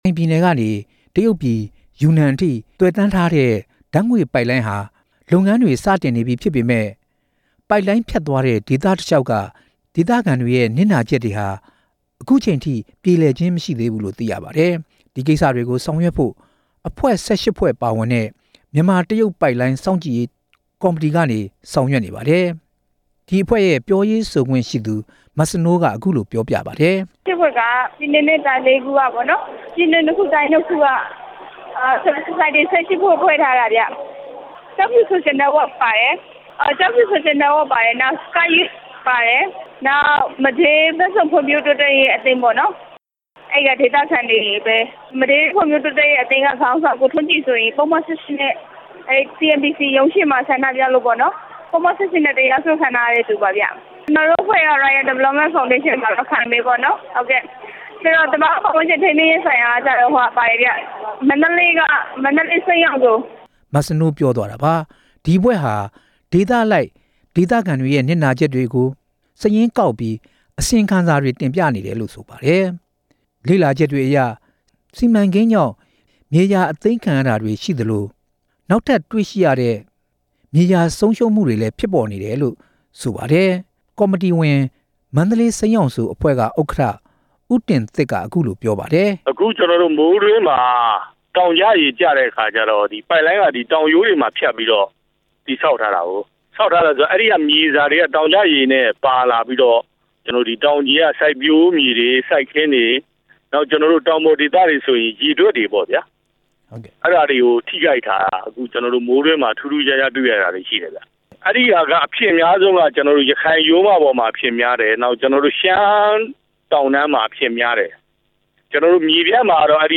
ဓာတ်ငွေ့ပိုက်လိုင်းနဲ့ ပတ်သက်ပြီး တင်ပြချက်